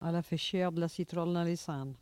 Patois - archives
Locution